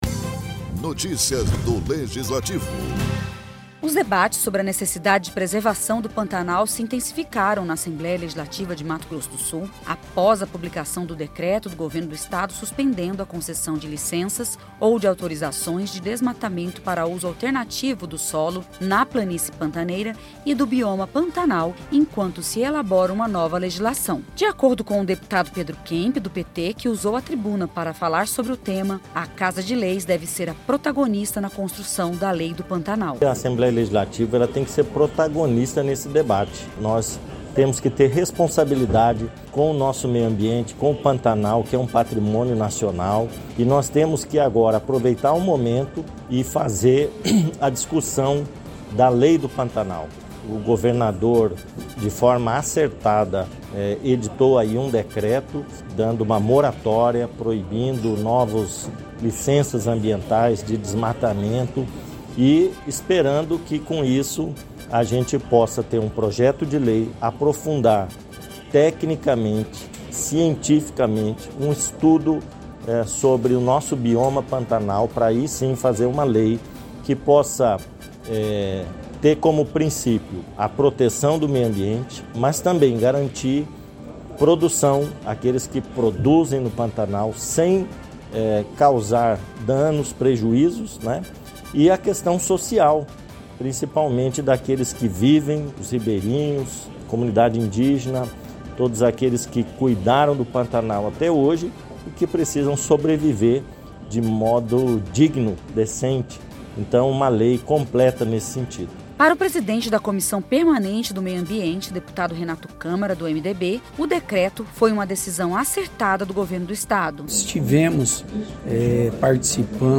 Deputado Pedro Kemp usou a tribuna para falar sobre a necessidade de uma legislação que preserve o pantanal, ao mesmo tempo em que dê alternativas sustentáveis para a produção e bem estar da população pantaneira.